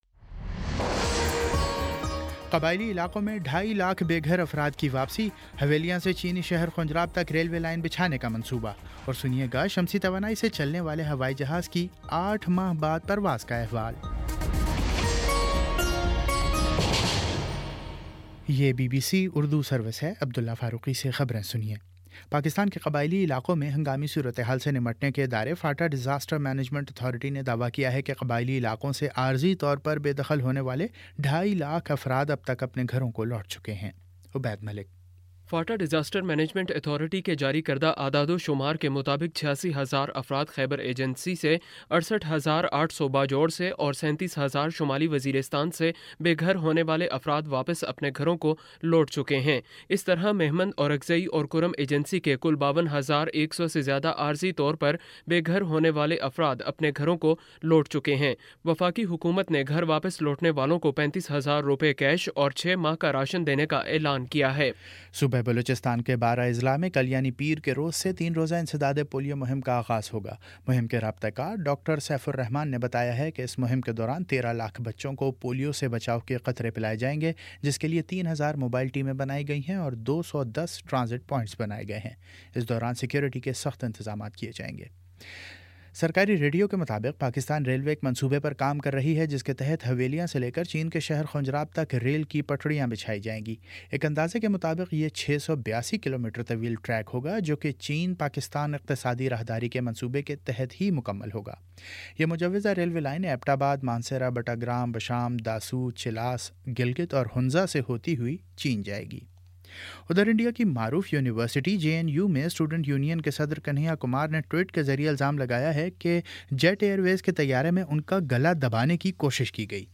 اپریل 24 : شام پانچ بجے کا نیوز بُلیٹن